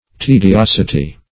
\Te`di*os"i*ty\